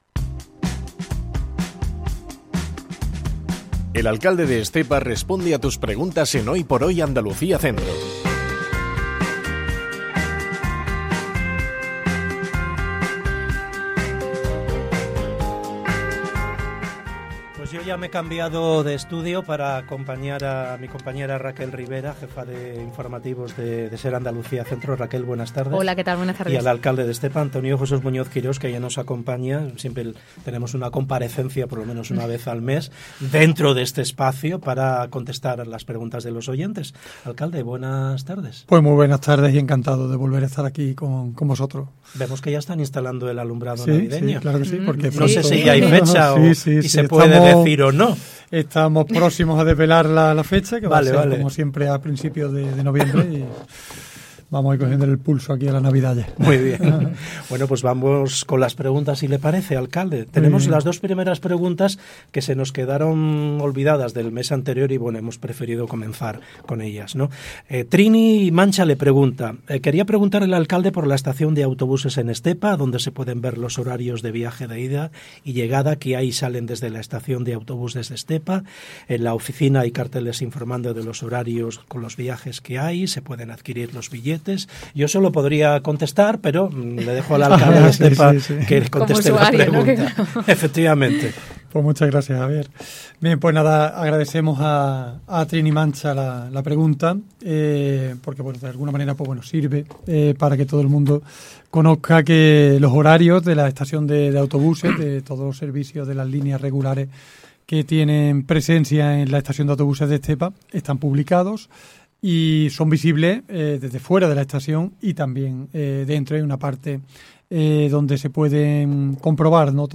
EL ALCALDE RESPONDE 23 DE OCTUBRE 2025 El alcalde de Estepa, Antonio Jesús Muñoz Quirós, responde a las preguntas de los oyentes en Hoy por Hoy SER Andalucía Centro.